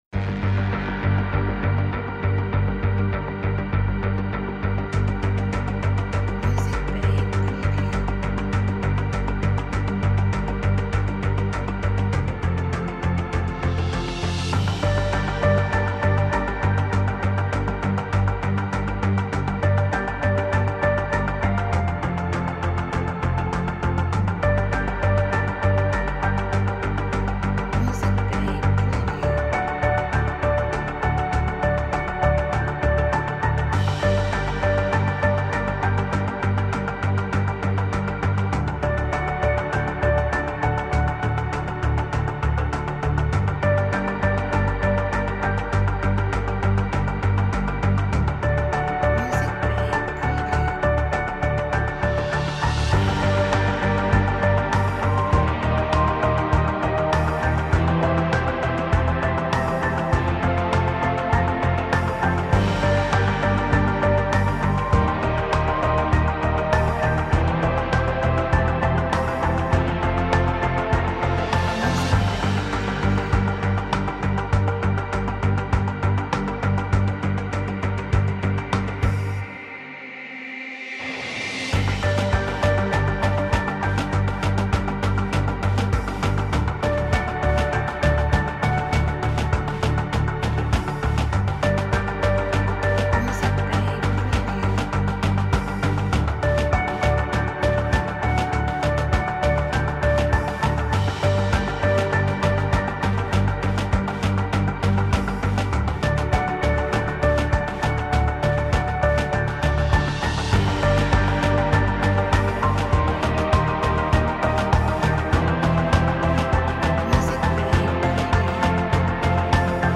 A category of tags that highlights suspenseful